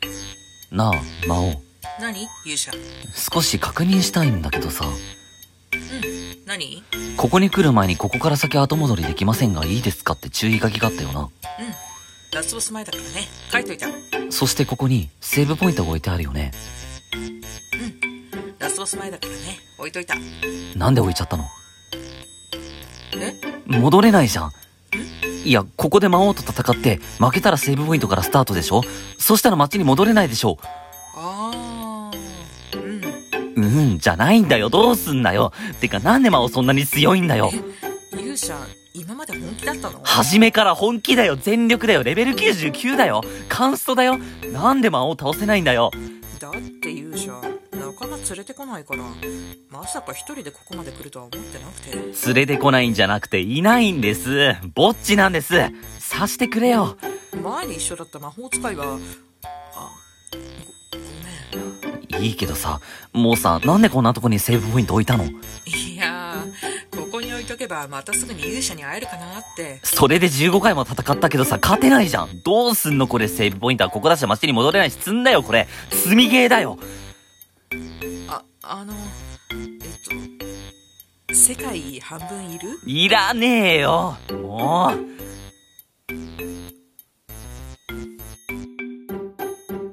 コラボ用 声劇「セーブポイントの有効活用」